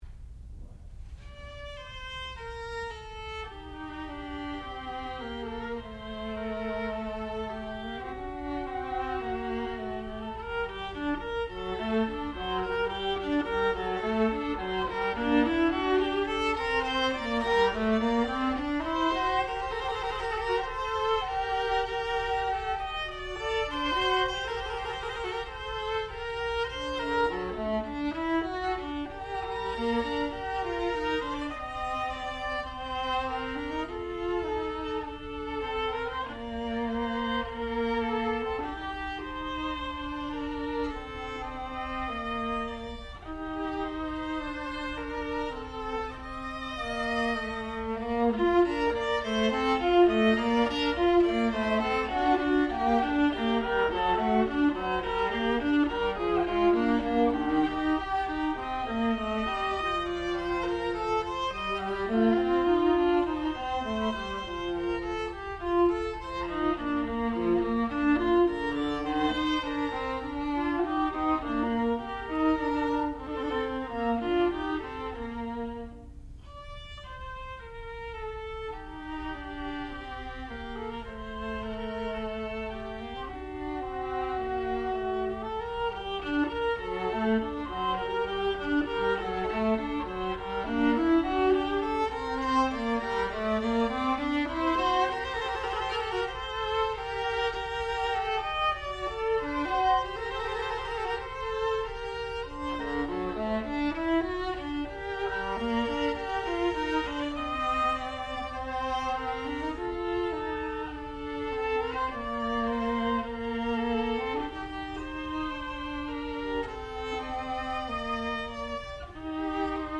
Duet for 2 Violas
sound recording-musical
classical music
viola